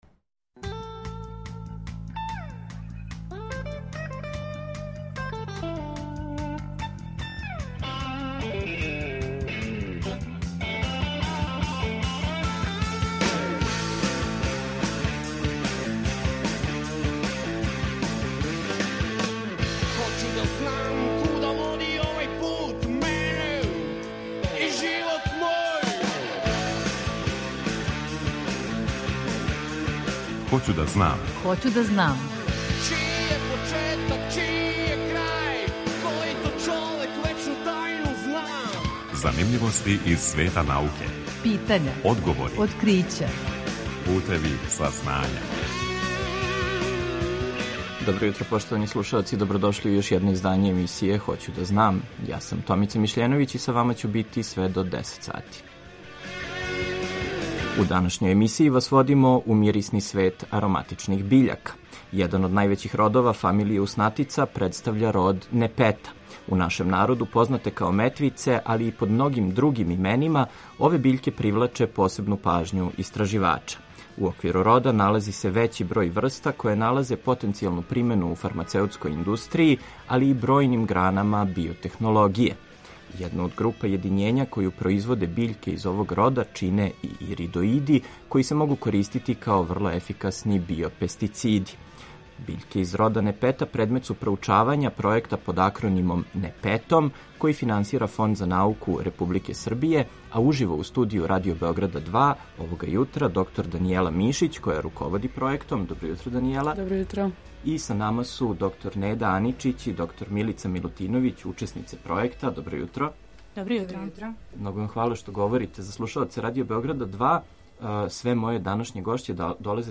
Eмисијa „Хоћу да знам“, посвећенa je популарној науци, која ће сваког четвртка од 9 до 10 сати, почев од 1. октобра 2020. доносити преглед вести и занимљивости из света науке, разговоре са истраживачима и одговоре на питања слушалаца.